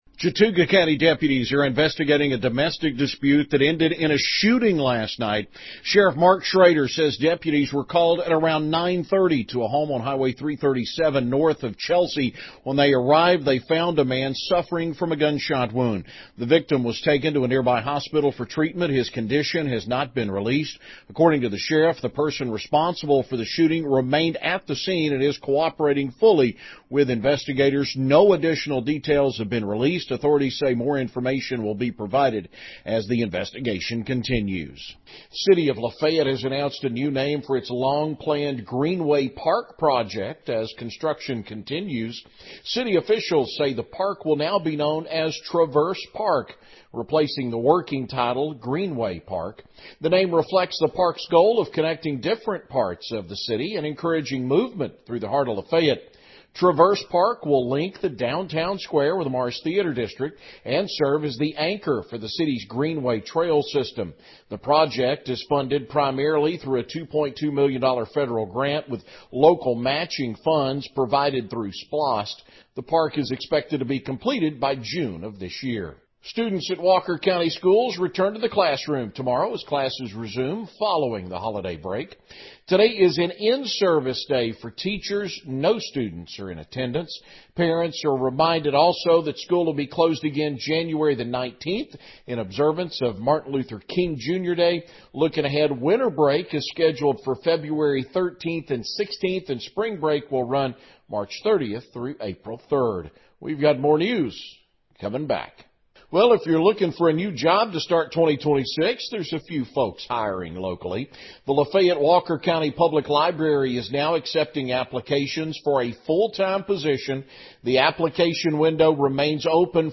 Georgia 93-7 and WQCH Local News Headlines